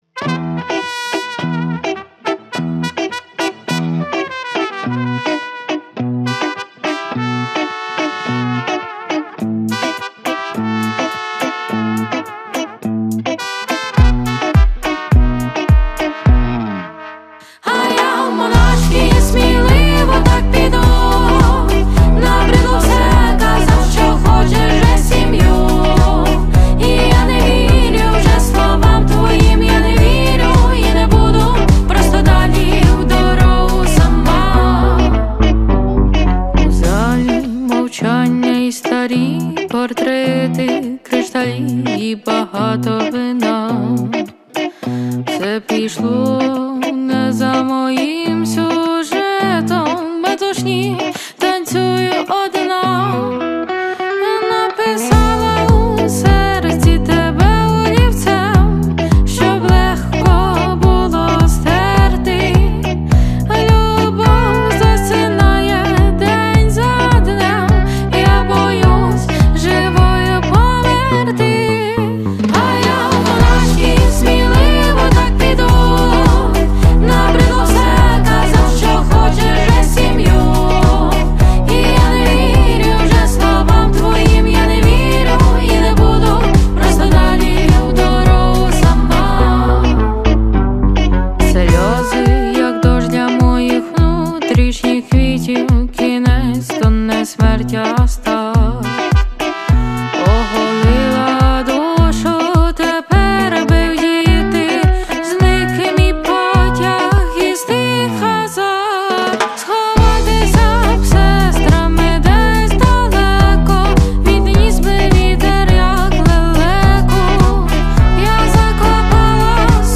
• Жанр: Альтернатива